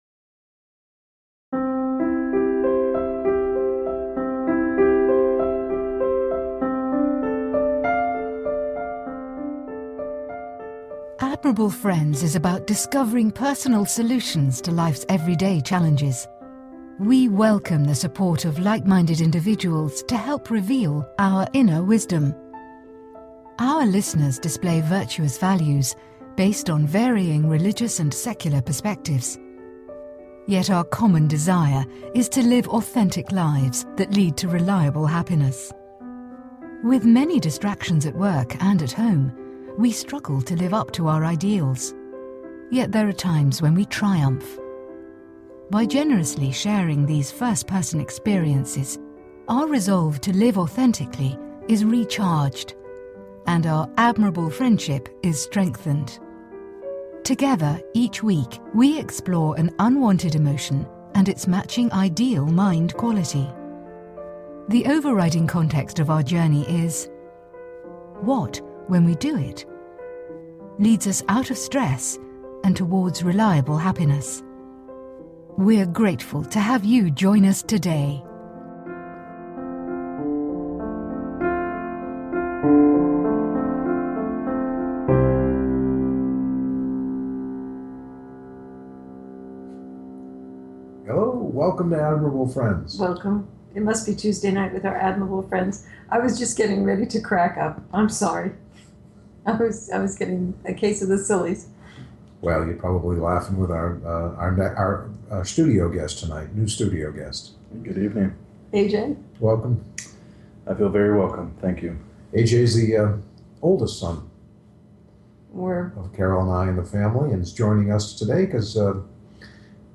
Talk Show Episode, Audio Podcast, Admirable Friends - Write Your Own Eulogy...